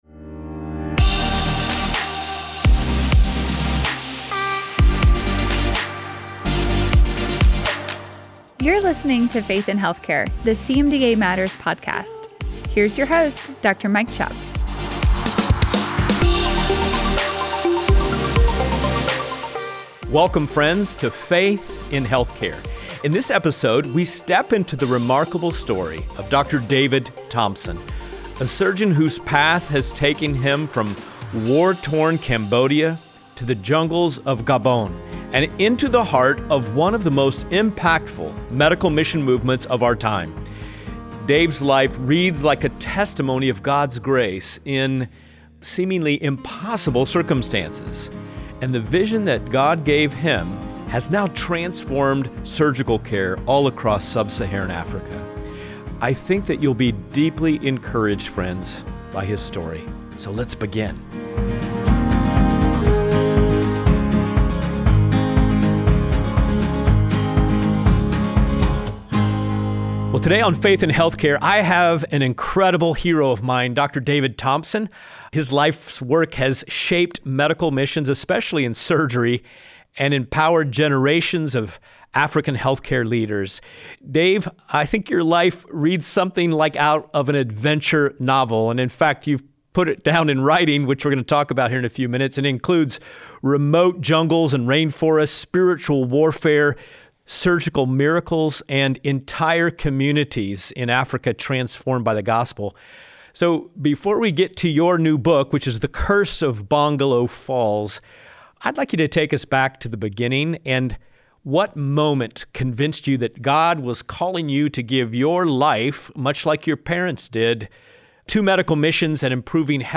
it is a weekly podcast that includes interviews with experts from Christian healthcare professionals. Topics include bioethics, healthcare missions, financial stewardship, marriage, family and much more.